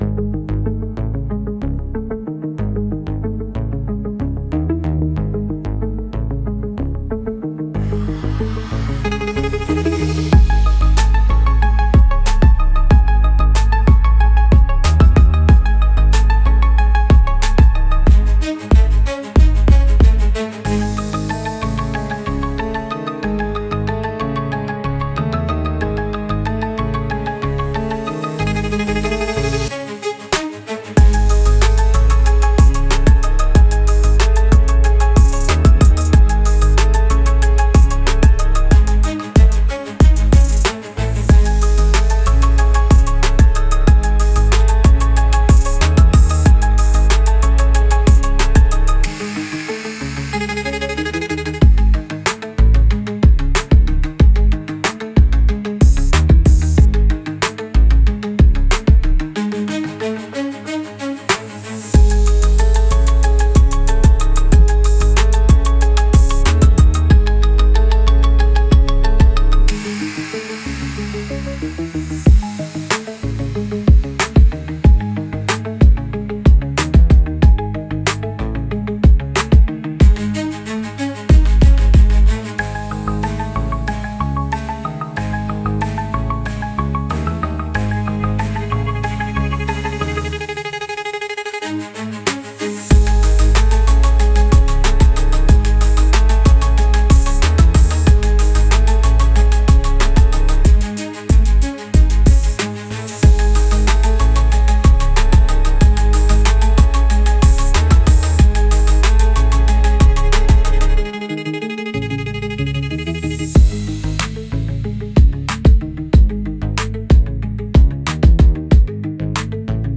Instrumental - Drag the Rhythm Down- 2.37 Mins